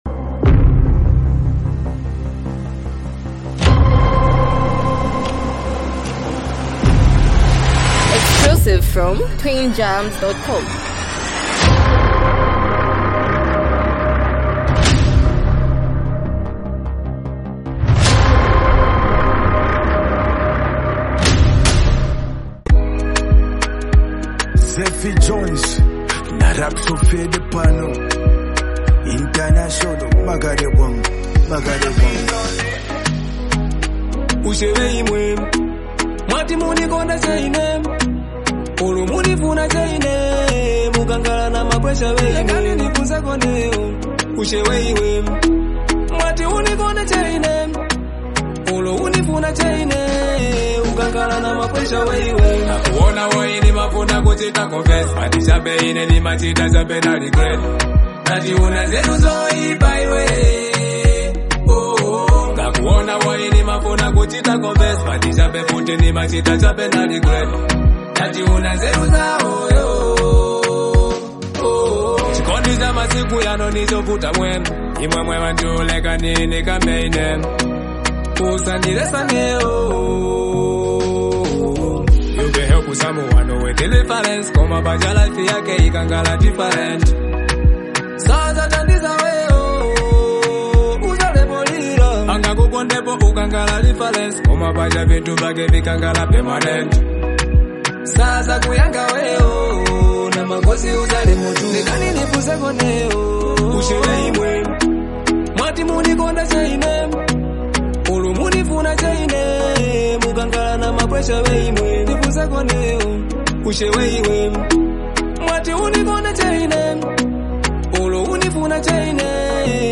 heartfelt song
beautiful love anthem
Through emotional lyrics and a soothing vibe